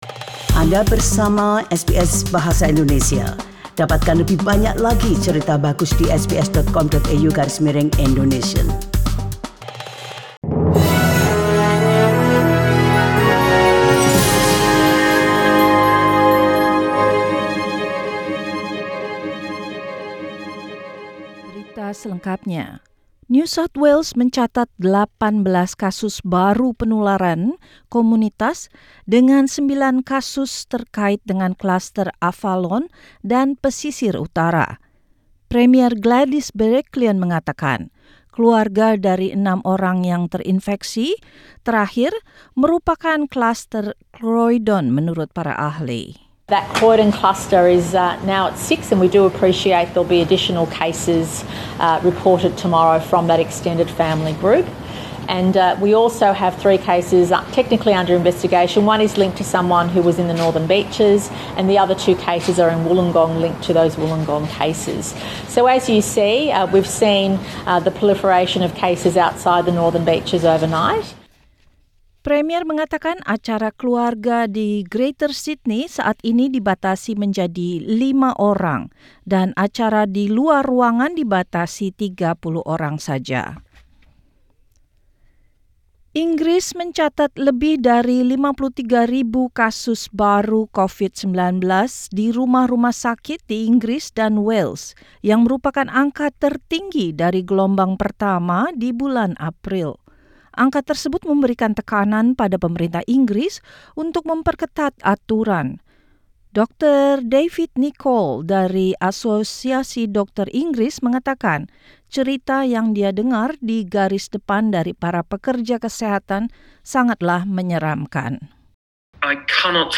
SBS Radio News in Indonesian - 30 December 2020